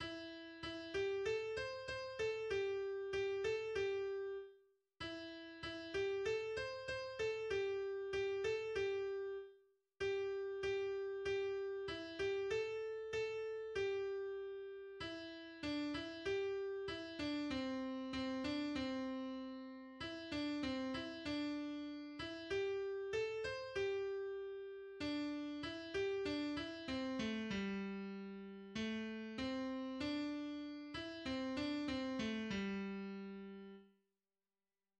茉莉花（まつりか）[1]中国民謡の一つ。